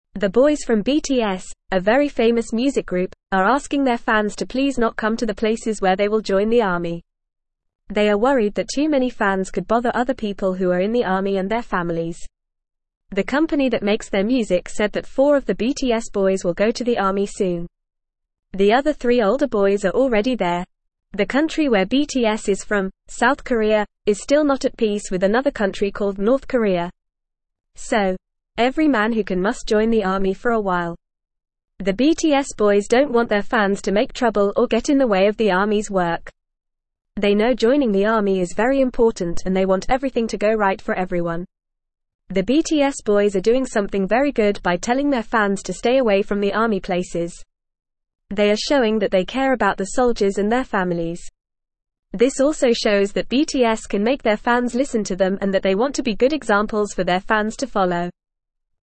Fast
English-Newsroom-Lower-Intermediate-FAST-Reading-BTS-Boys-Ask-Fans-to-Stay-Away-from-Army.mp3